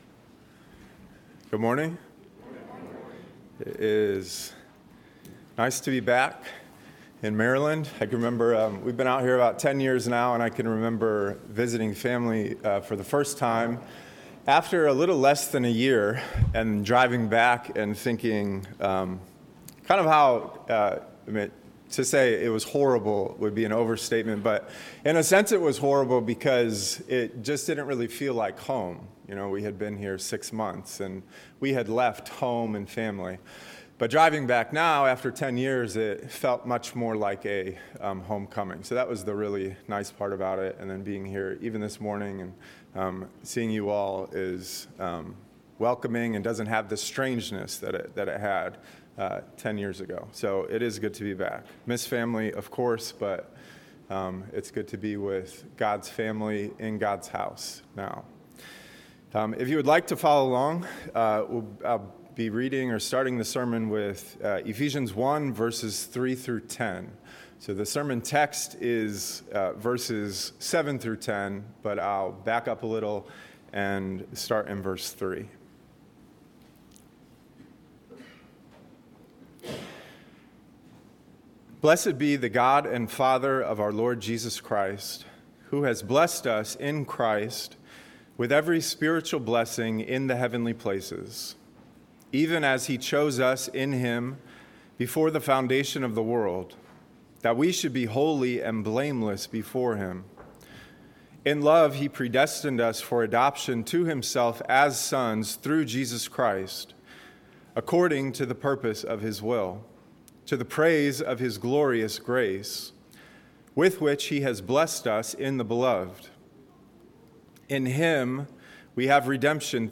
A message from the series "Ephesians 2025."